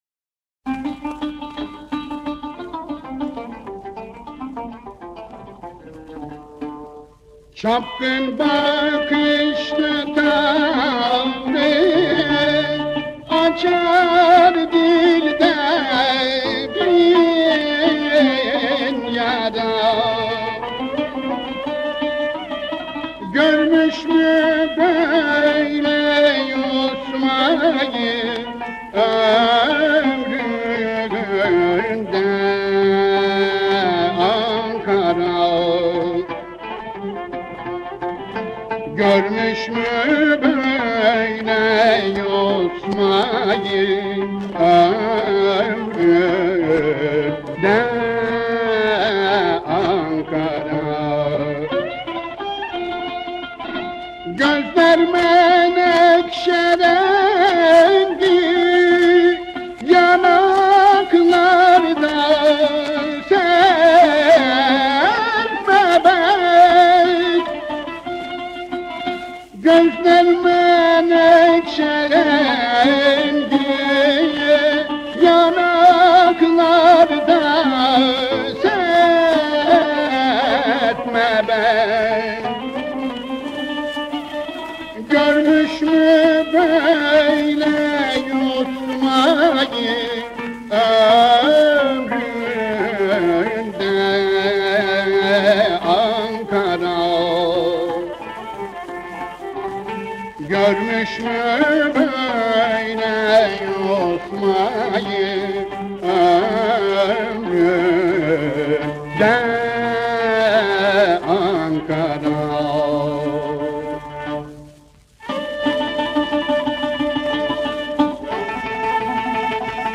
Eser: Çapkın bakışl tavrı açar dilde bin yara Bestekâr: Belirsiz Güfte Sâhibi: Belirsiz Makam: Kürdili Hicazkar Form: Şarkı Usûl: Aksak Güfte: - Kaynak: Sanat Müziği Notaları Diğer Bilgiler: Arşiv No: -